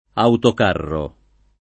autocarro [ autok # rro ] s. m.